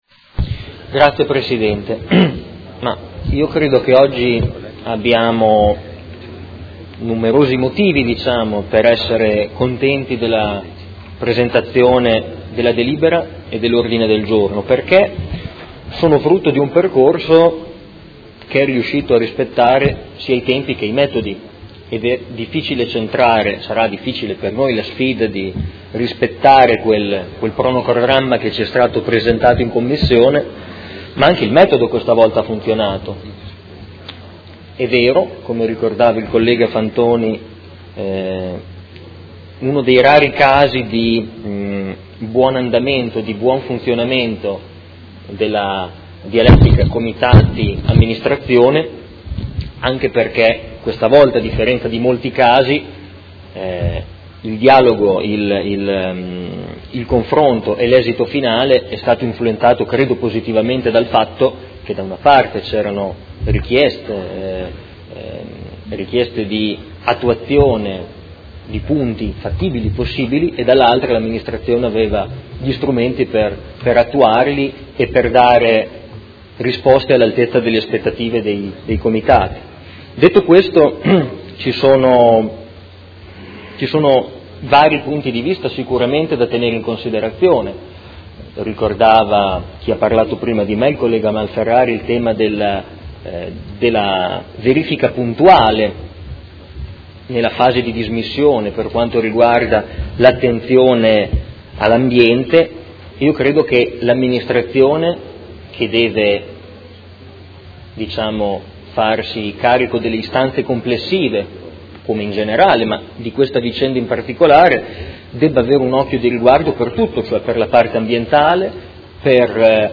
Marco Forghieri — Sito Audio Consiglio Comunale